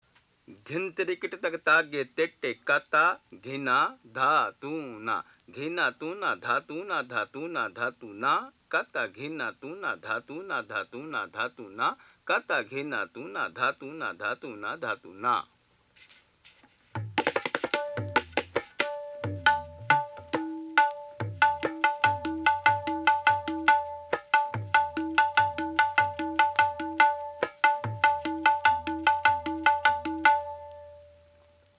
Listen to Keramatulla Khan speak and perform this mohra[1]: